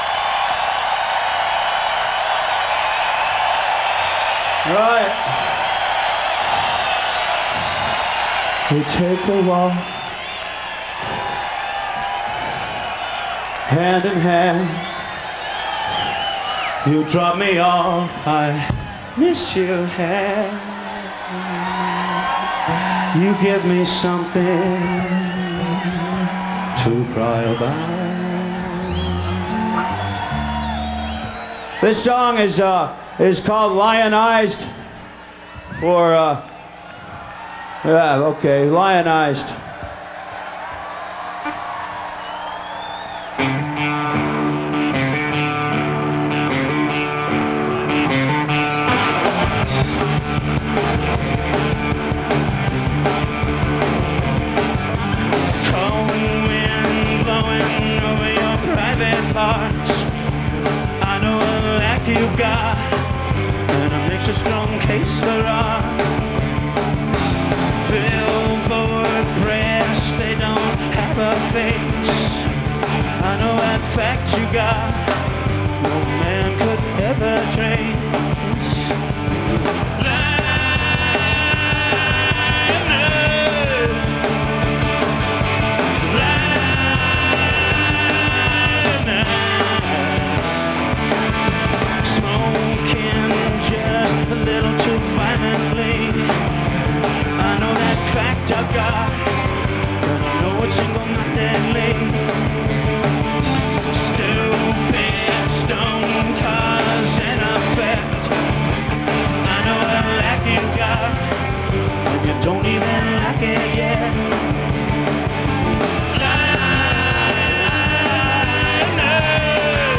Live In 1992
Source: TV